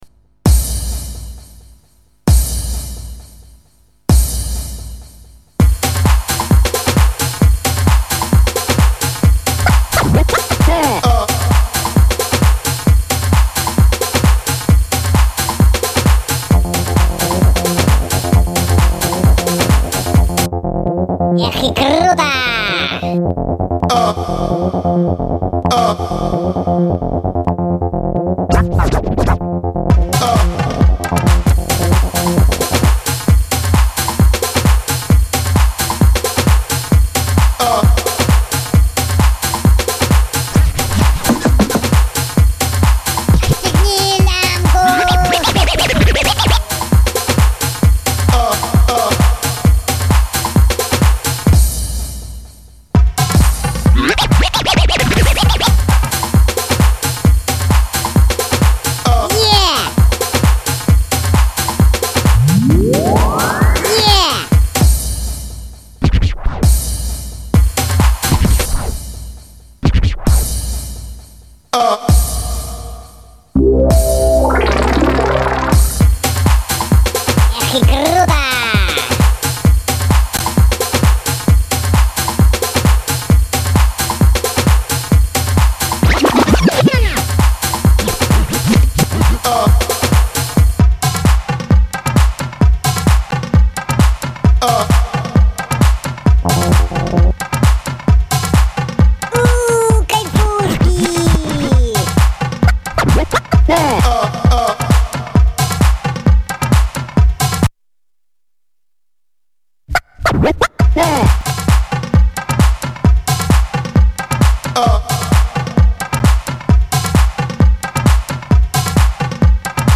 Жанр: Dancecore